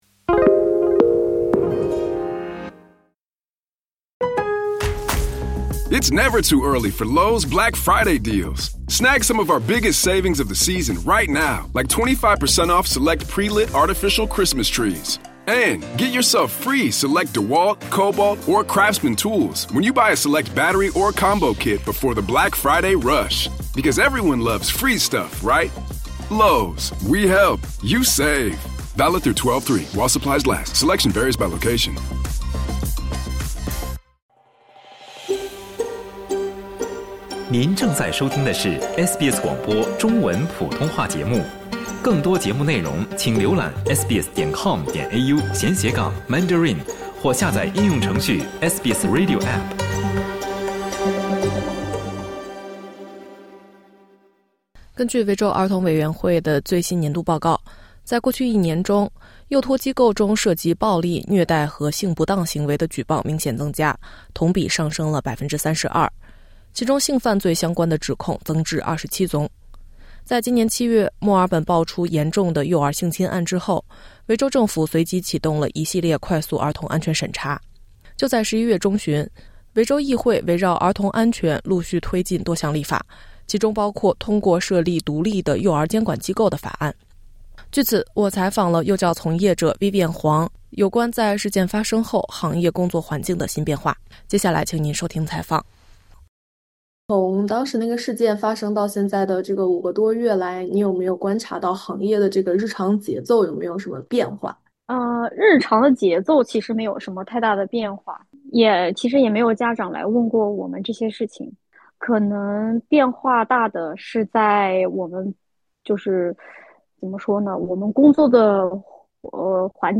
就在11月中旬，维州议会围绕儿童安全陆续推进多项立法，其中包括通过设立独立幼教监管机构的法案。那么幼教行业目前正在经历哪些“新常态”呢？（点击播客，收听采访）